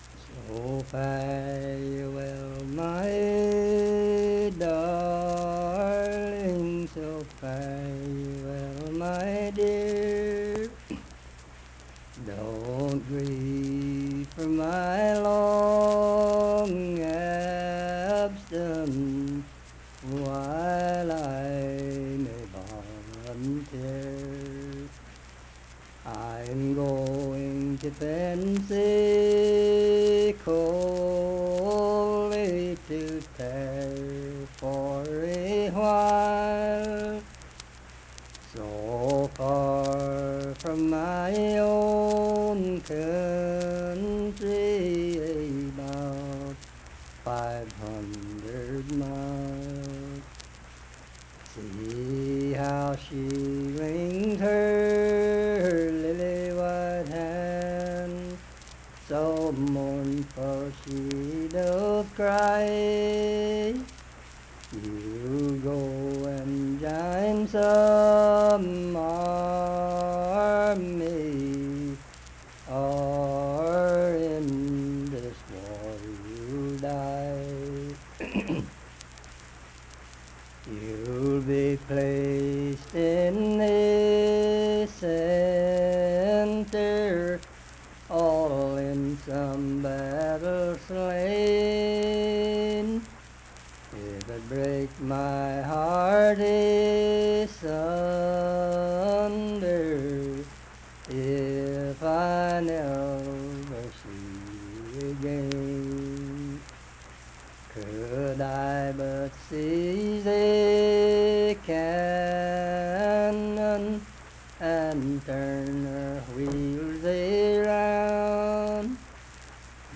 Unaccompanied vocal music
Verse-refrain 5(4).
Voice (sung)
Harts (W. Va.), Lincoln County (W. Va.)